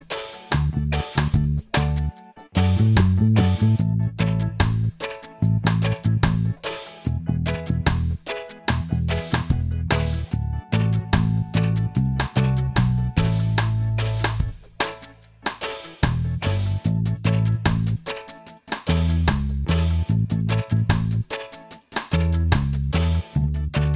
Minus Solo Guitar And Organ Reggae 3:04 Buy £1.50